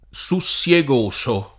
sussiegoso [ S u SSL e g1S o ] agg.